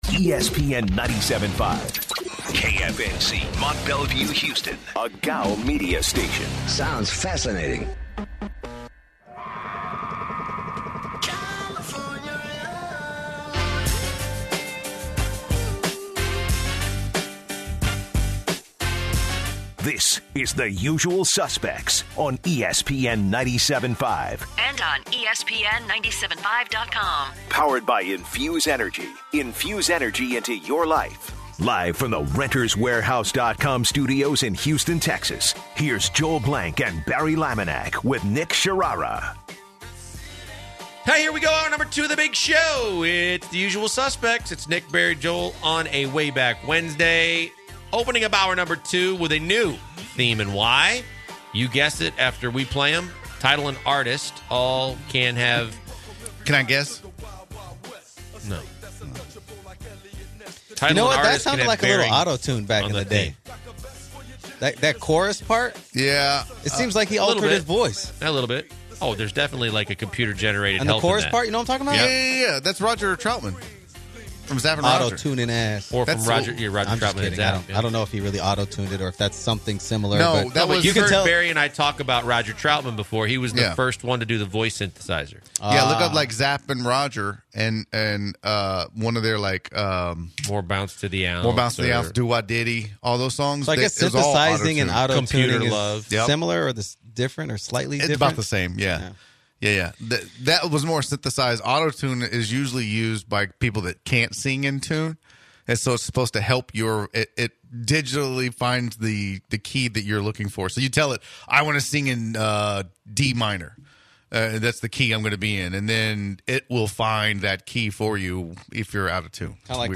The Usual Suspects start the second hour talking today’s music theme of Way Back Wednesday. The guys talk about Astros baseball and the race to claim the AL West division. They also take calls from listeners and explore the Astros’ playoff prospects.